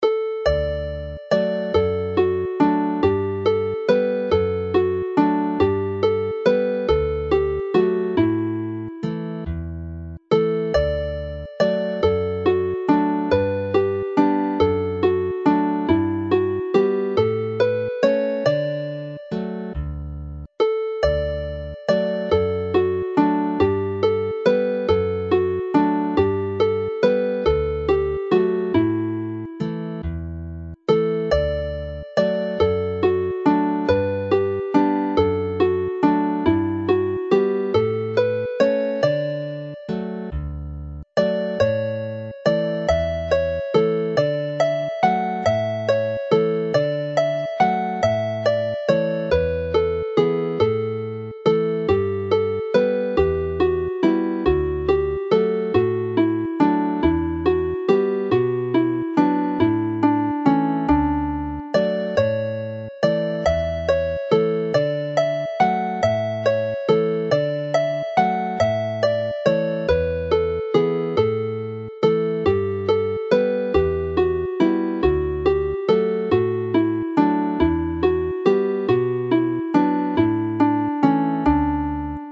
Llawn Hyder (full of confidence) is a cheery Jig in a major key to finish the set on a happy note.
Play the tune slowly